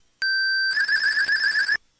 描述：带32mb卡和i kimu软件的gameboy样品